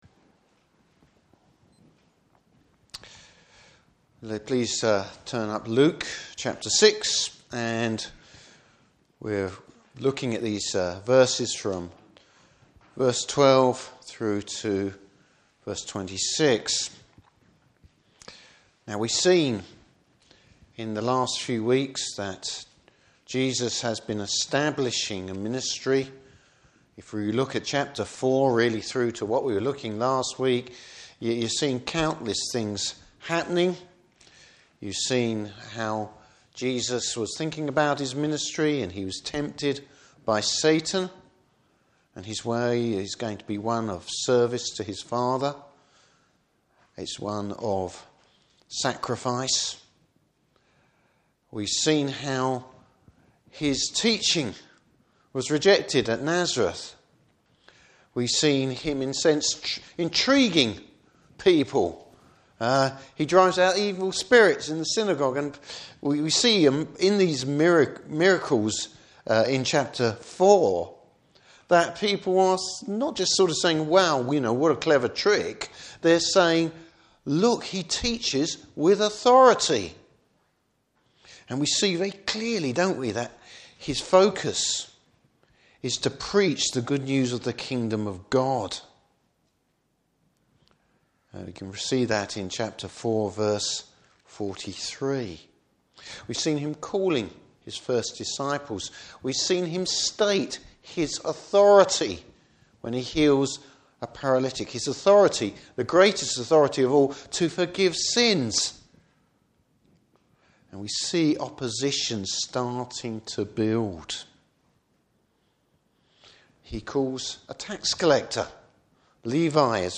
Service Type: Morning Service Bible Text: Luke 6:12-26.